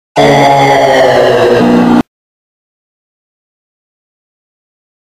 ZONK Sound OHNE klatschen!.mp3